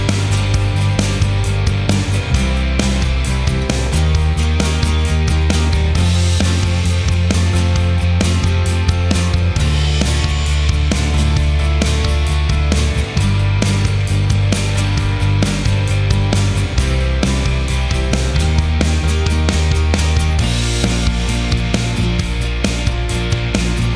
Minus All Guitars Duets 4:40 Buy £1.50